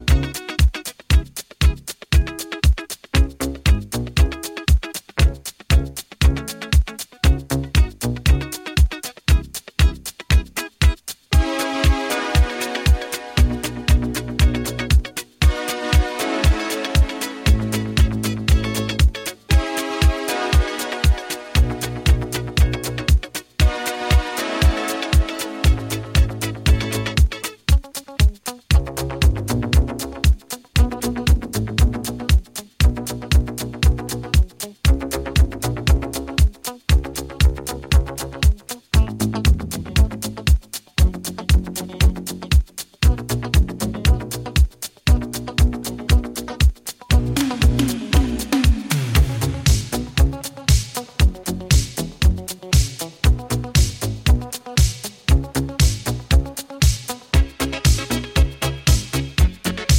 На этом диске хиты POP исполнителей!